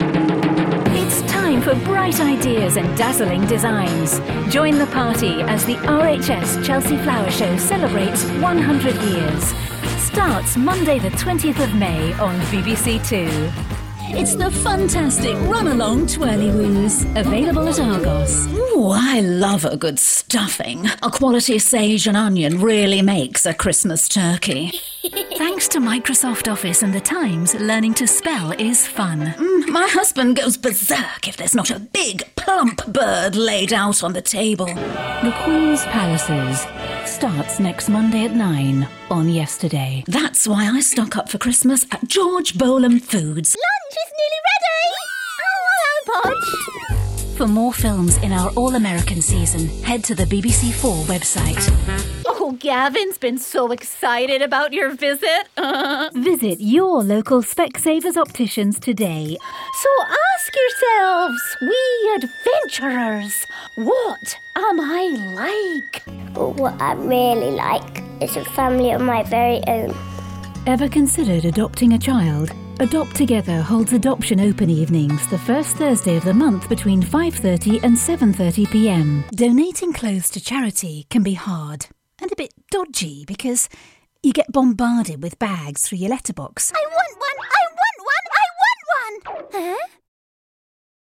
Female
British English (Native)
Assured, Authoritative, Confident, Corporate, Engaging, Reassuring, Smooth, Warm, Versatile
My vocal style is clear, warm and rich, with a naturally reassuring quality that inspires trust.
Museum Audioguide Eng Test.mp3
Microphone: Neumann TLM103